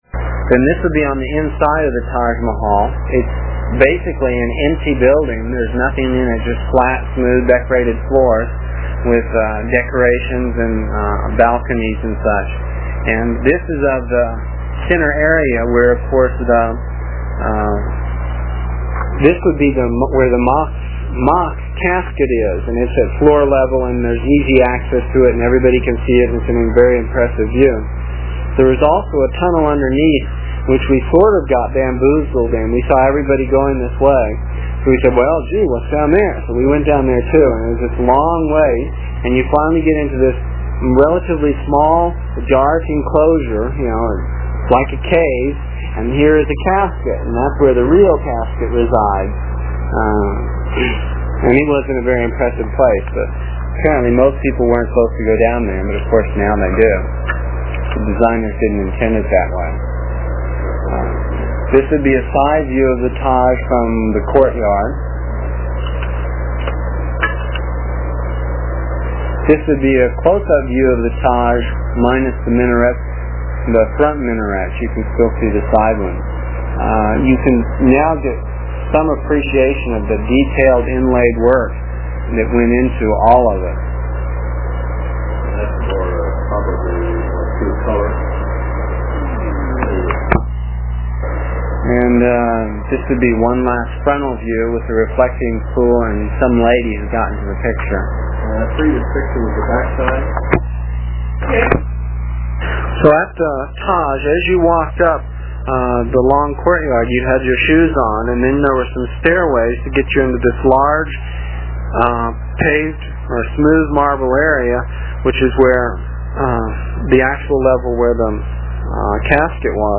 It is from the cassette tapes we made almost thirty years ago. I was pretty long winded (no rehearsals or editting and tapes were cheap) and the section for this page is about eight minutes and will take about three minutes to download with a dial up connection.